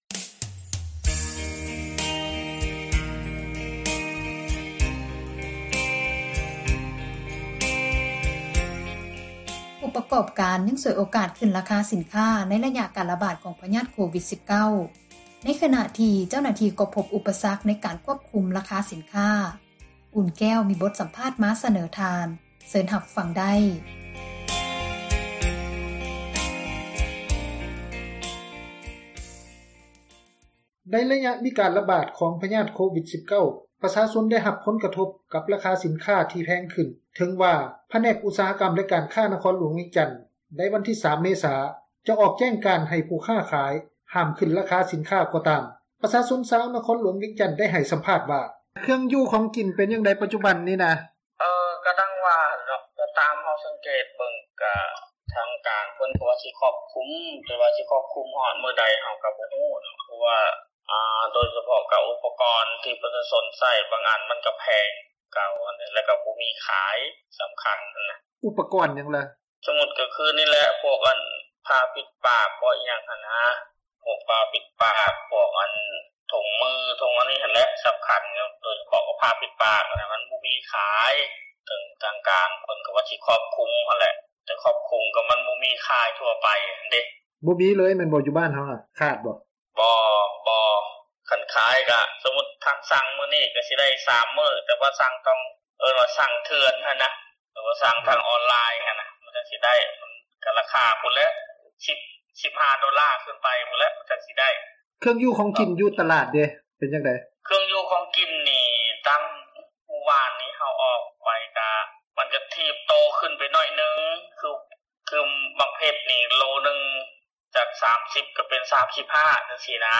ໃນໄລຍະການລະບາດ ຂອງພຍາດໂຄວິດ19, ປະຊາຊົນ ໄດ້ຮັບຜົນກະທົບ ຍ້ອນລາຄາສິນຄ້າທີ່ແພງຂຶ້ນ ເຖິງວ່າ ຜແນກອຸດສາຫະກັມ ແລະ ການຄ້າ ນະຄອນຫລວງ ໃນວັນທີ 3 ເມສາ ຈະອອກແຈ້ງການ ໃຫ້ຜູ້ຄ້າຂາຍ ຫ້າມຂຶ້ນລາຄາສິນຄ້າ ກໍຕາມ. ດັ່ງ ປະຊາຊົນ ຊາວນະຄອນຫລວງວຽງງຈັນ ໄດ້ໃຫ້ສັມພາດ ວ່າ: